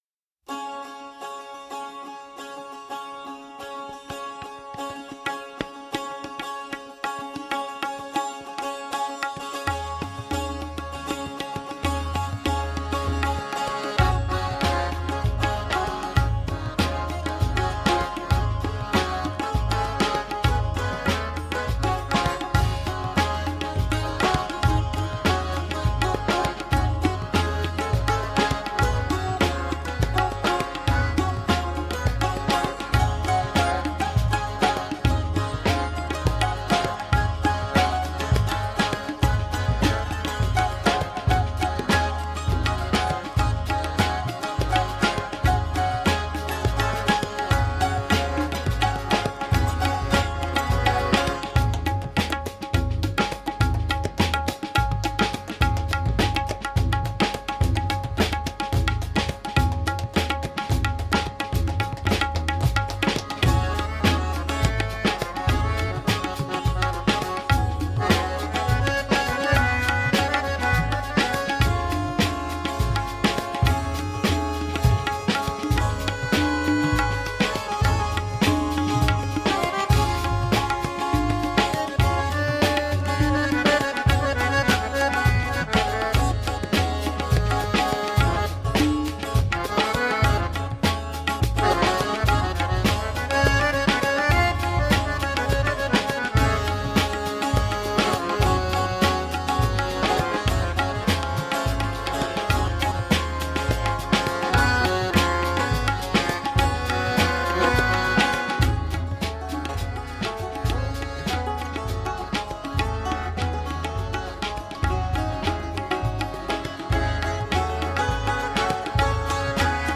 Middle eastern/balkan music and new age.
Oud, Bouzouki, saz, Flutes and Vocals
Piano, Accordion and Harmonium
Tabla, Drum set and Frame drums